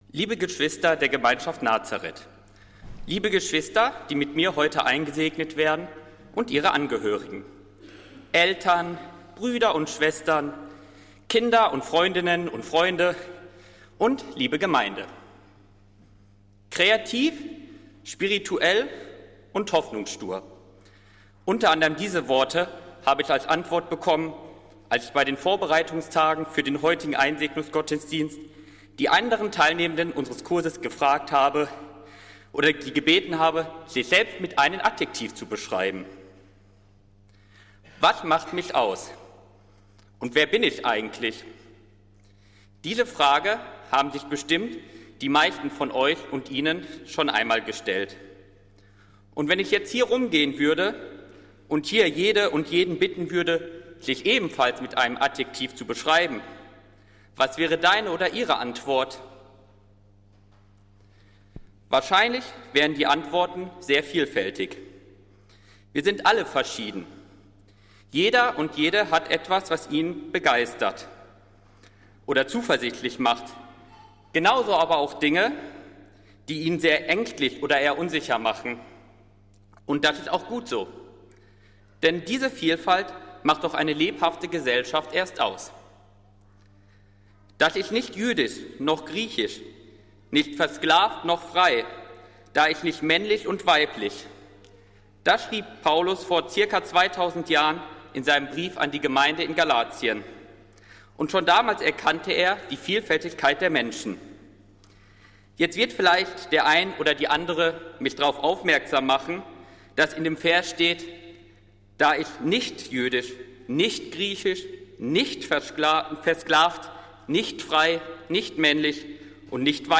Predigt des Einsegnungsgottesdienstes der Diakonischen Gemeinschaft Nazareth aus der Zionskirche am Sonntag, den 22.09.2024